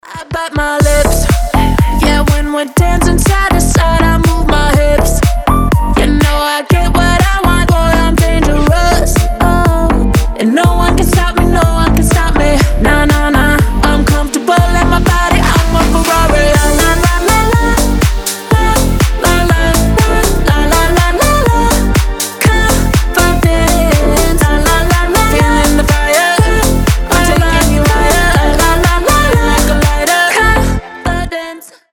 громкие
зажигательные
мощные басы
future house
slap house